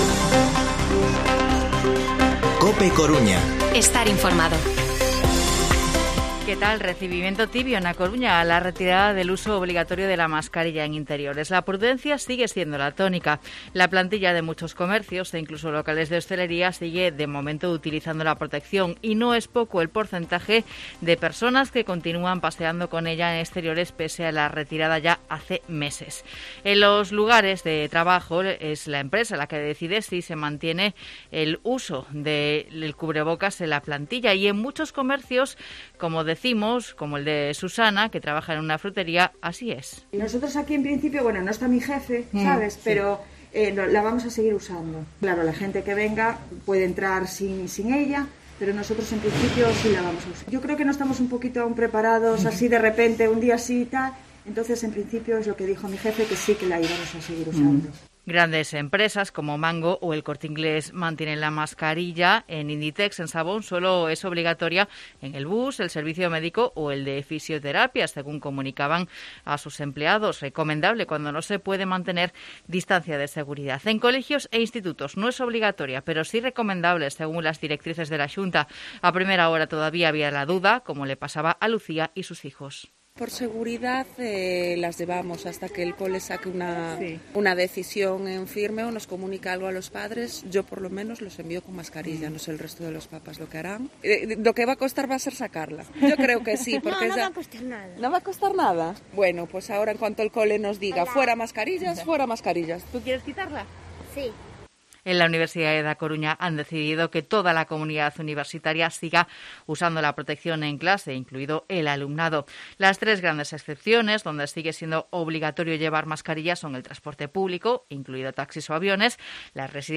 Informativo Mediodía COPE Coruña miércoles, 20 de abril de 2022 14:20-14:30